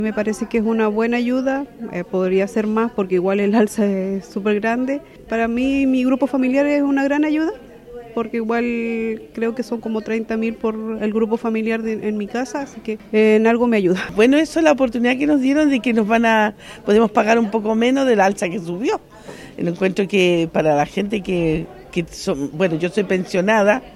En ese lugar, beneficiarias del subsidio valoraron la iniciativa.